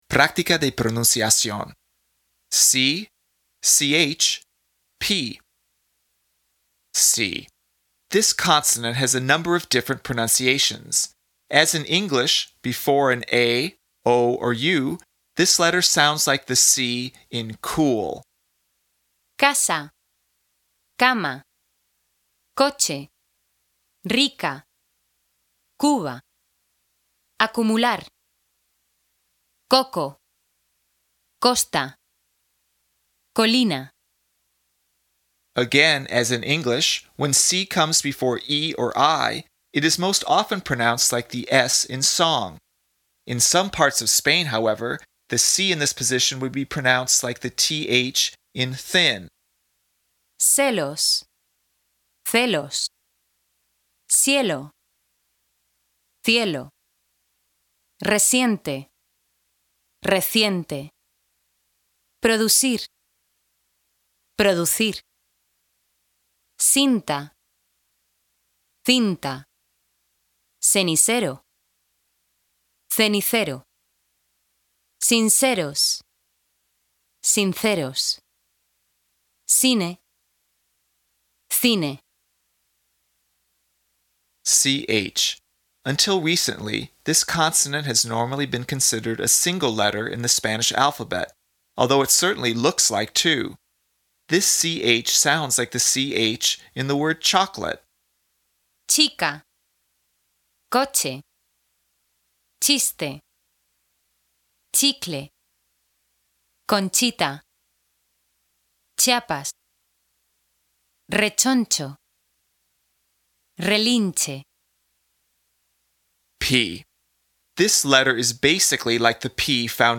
PRÁCTICA DE PRONUNCIACIÓN
This “ch” sounds like the “ch” in the word “chocolate.”
The Spanish “p” is a little more restrained, but to most people not really distinguishable from the English “p.”